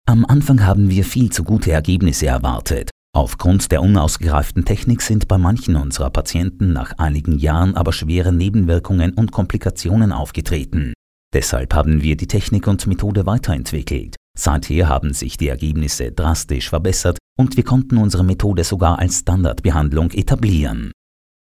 Freundliche tiefe Männerstimme, akzentfrei und hochdeutsch.
Natural Speak
Voice Over